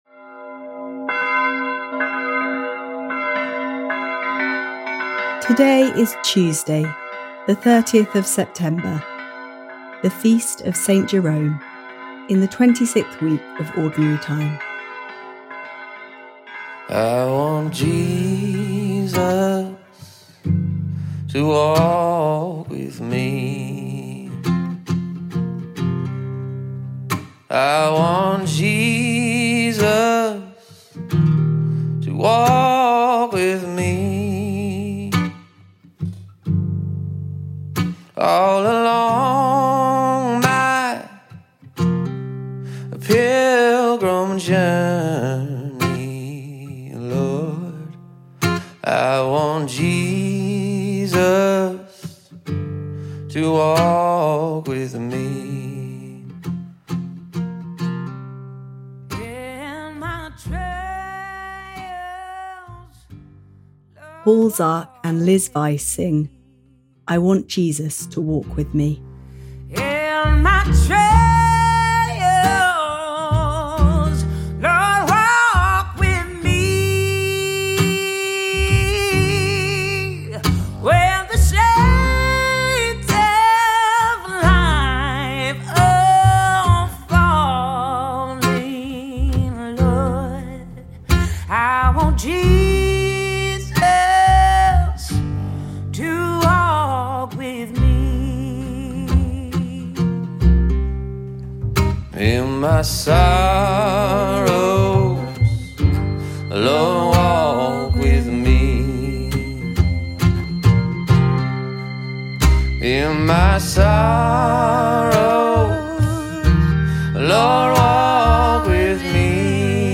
Now we hear the reading repeated.